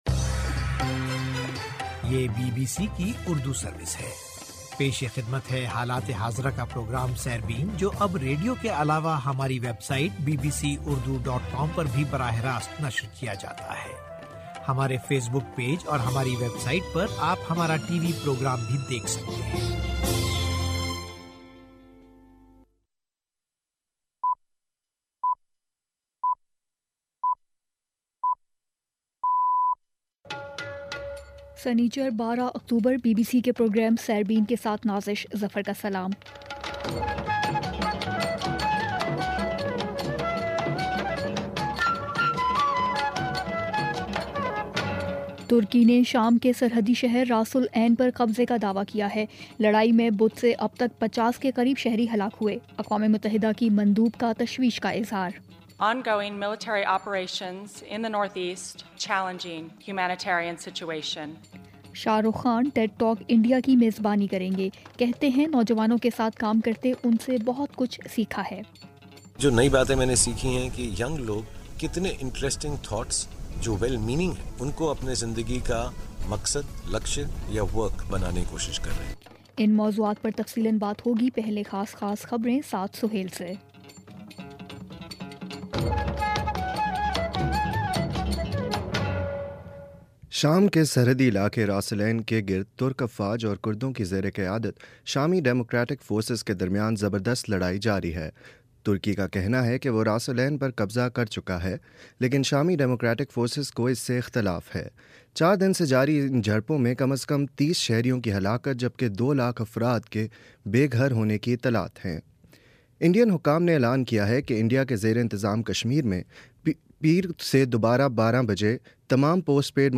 سنیچر 12 اکتوبر کا سیربین ریڈیو پروگرام